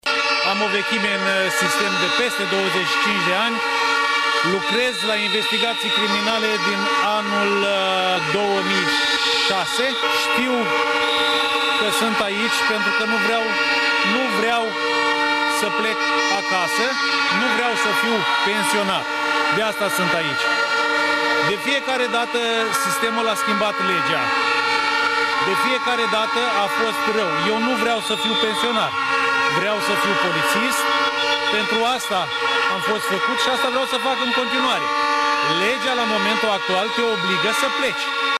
UPDATE – Zeci de salariați din cadrul Ministerului Afacerilor Interne au protestat, astăzi, în fața Prefecturii Neamț, nemulțumiți de prevederile Ordonanței „trenuleț”, care le îngrădește o serie de drepturi.
9-ian-rdj-17-Vox-protest.mp3